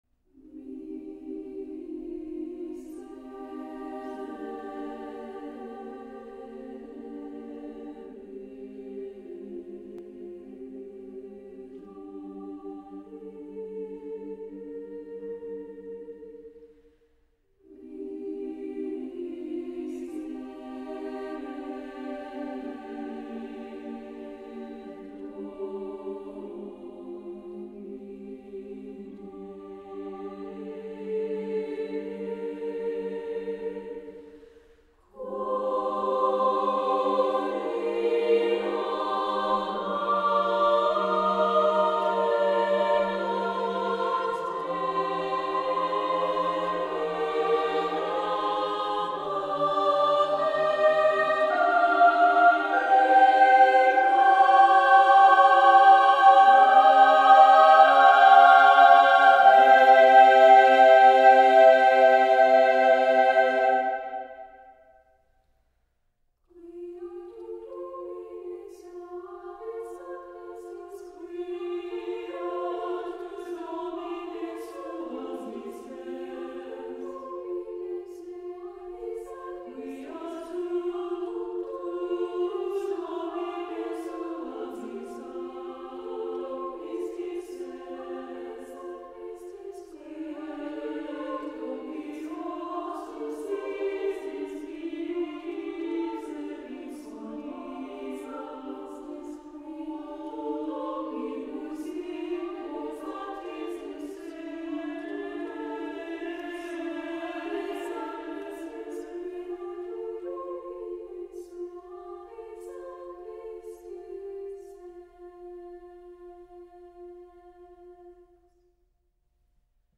Voicing: "SSA divisi"